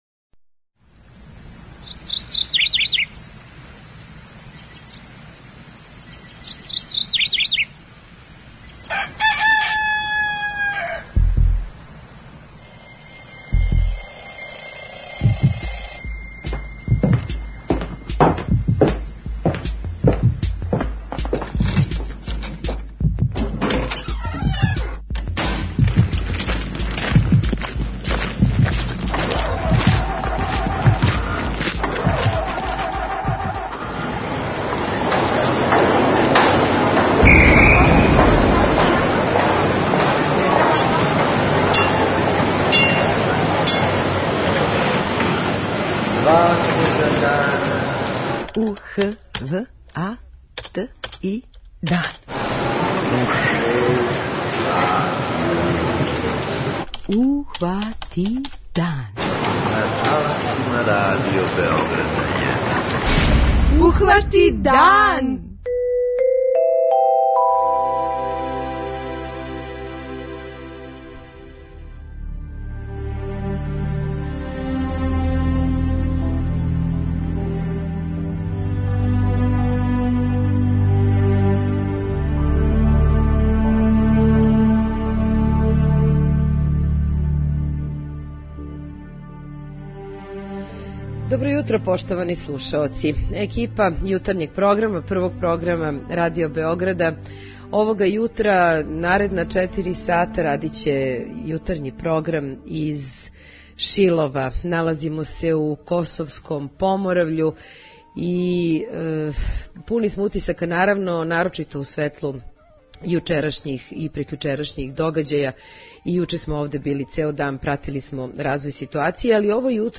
Ухвати дан - уживо из Шилова
Јутарњи програм Ухвати дан уживо из Шилова!